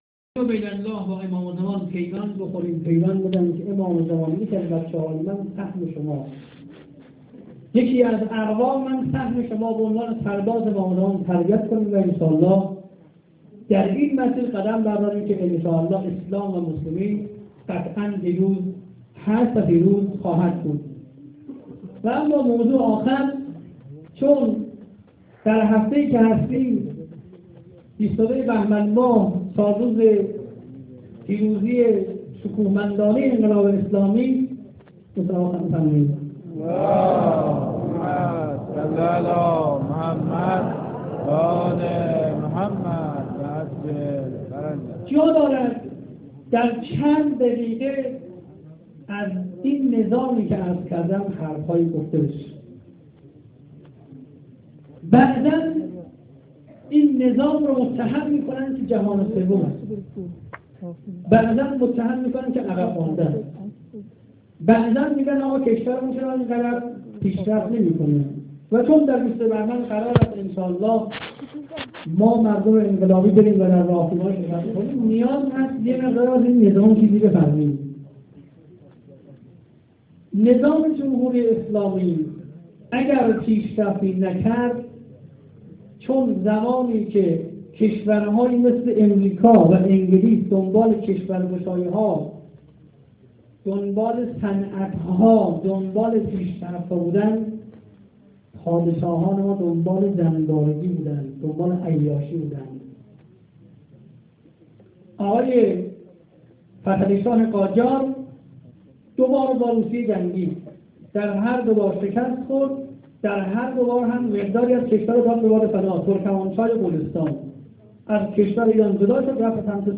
ادامه-خطبه-دوم.amr